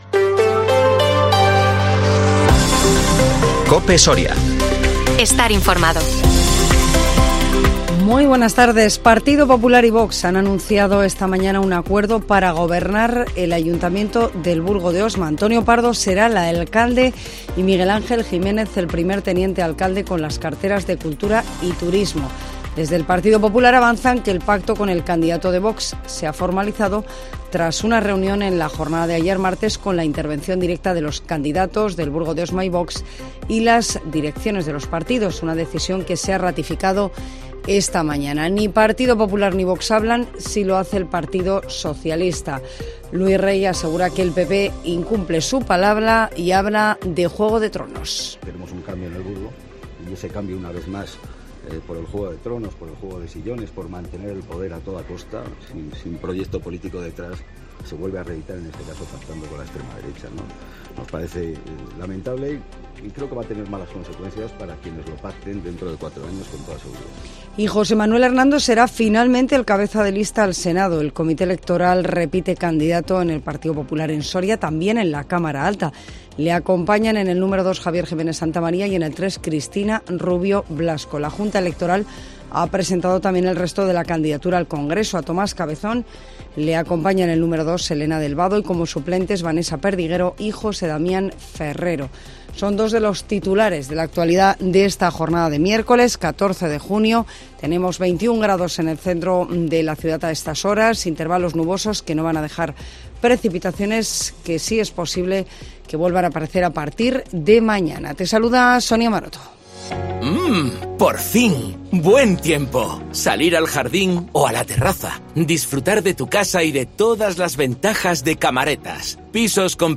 INFORMATIVO MEDIODÍA COPE SORIA 14 JUNIO 2023